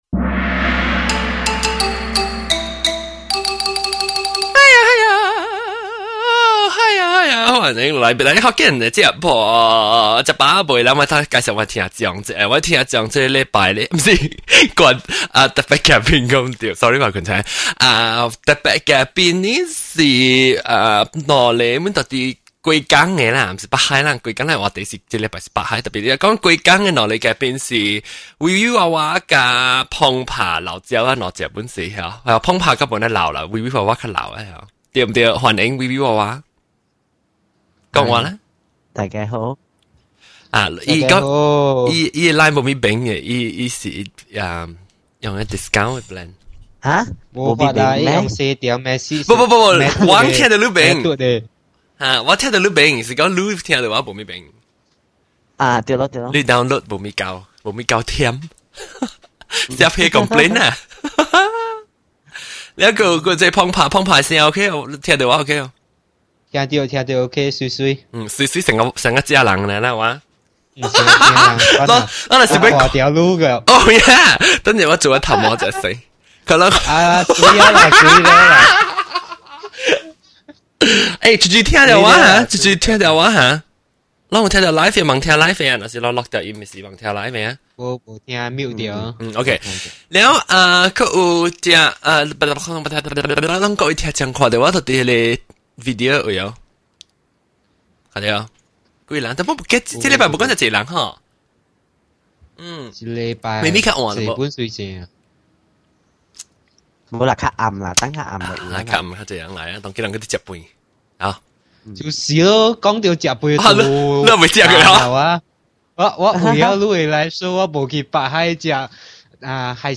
Since we have so many listeners not from the Penang island, we decided to invite those who live on the mainland to chat with us.
But since we all speak Hokkien, we are all connected by the dialect.